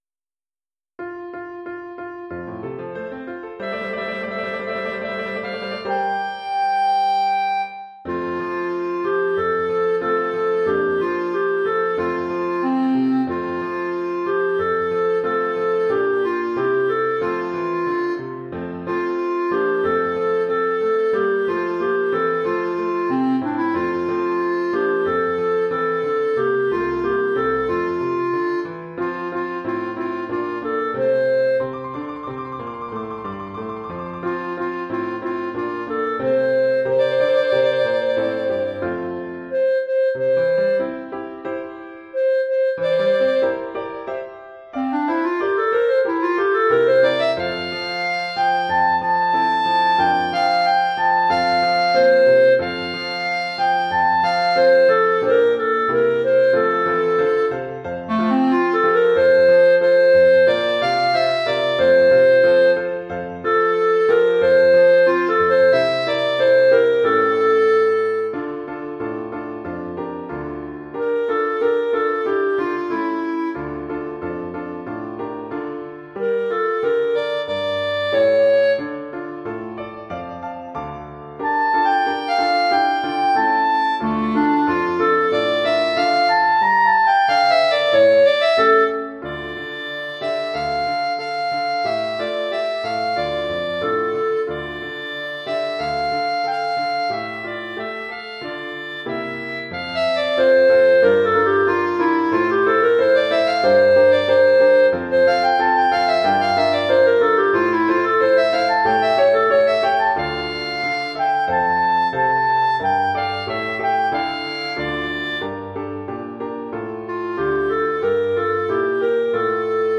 Formule instrumentale : Clarinette et piano
Chanson traditionnelle,
arrangée pour clarinette et piano